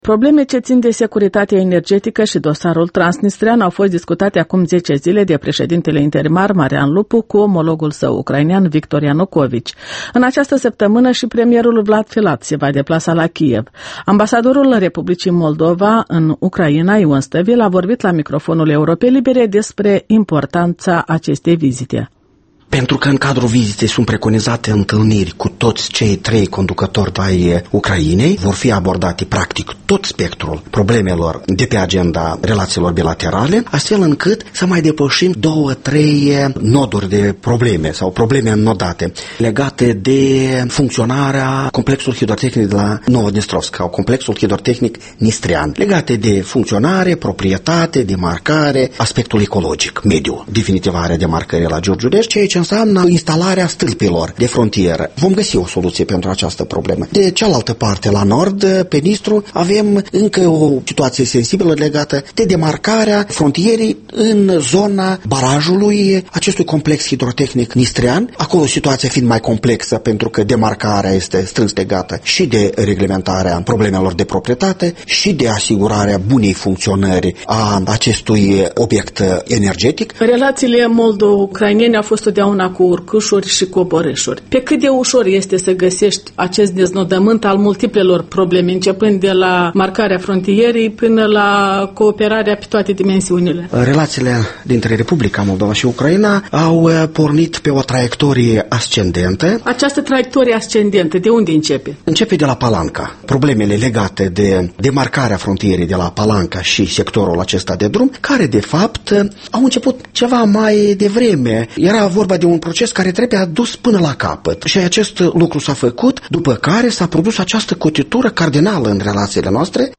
Interviul dimineții la Europa Liberă: cu ambasadorul Ion Stăvilă despre relațiile cu Ucraina